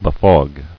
[be·fog]